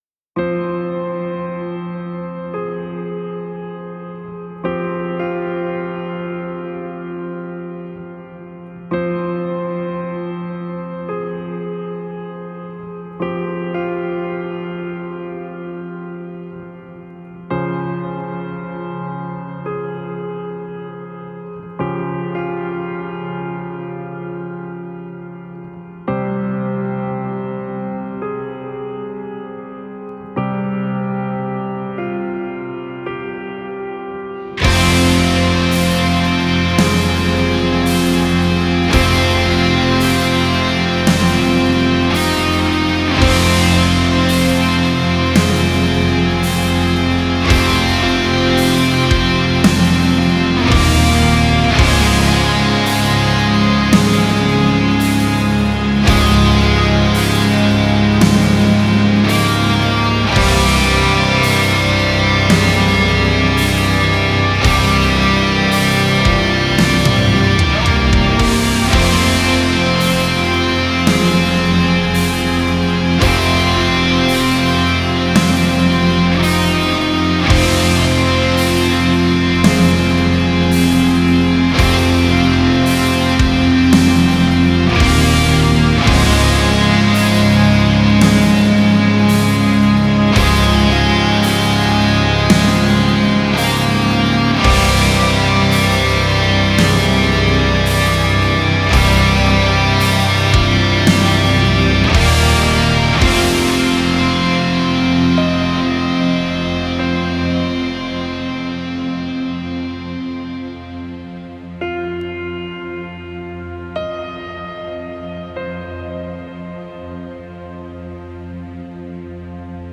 Genre: Melodic Doom / Funeral Doom